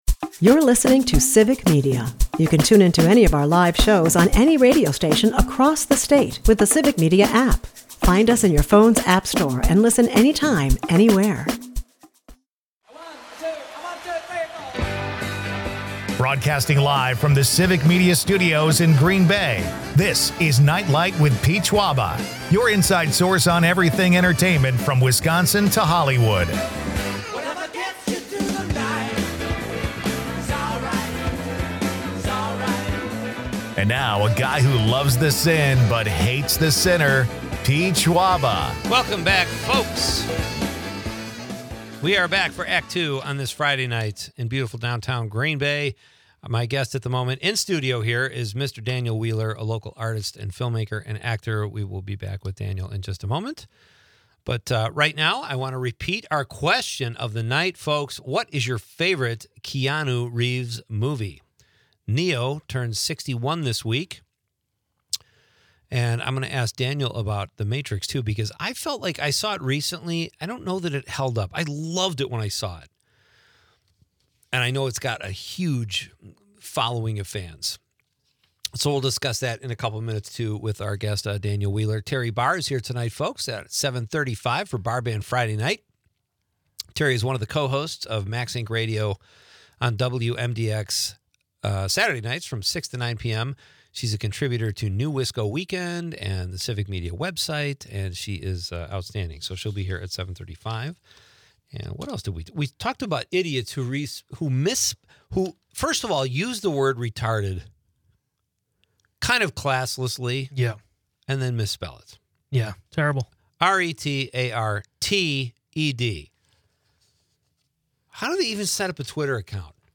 The night wraps up with listener texts and a preview of upcoming shows.
Leave the stress of the day behind with entertainment news, comedy and quirky Wisconsin.